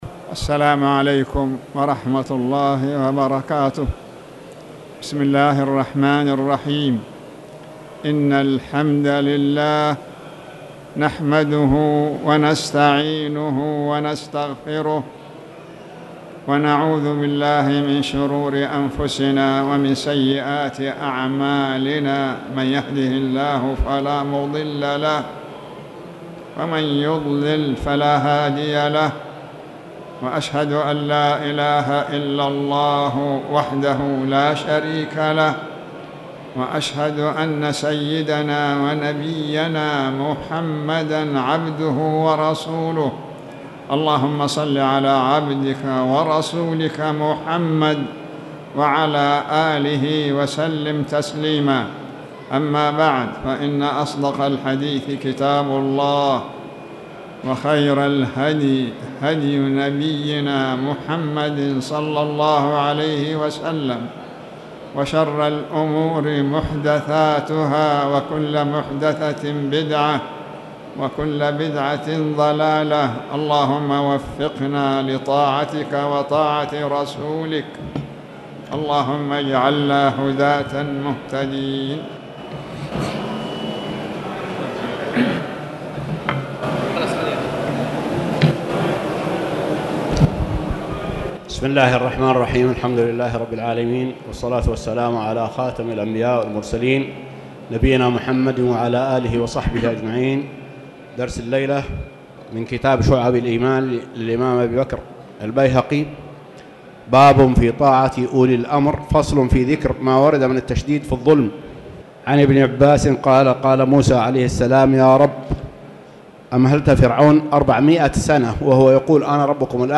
تاريخ النشر ٢٠ صفر ١٤٣٨ هـ المكان: المسجد الحرام الشيخ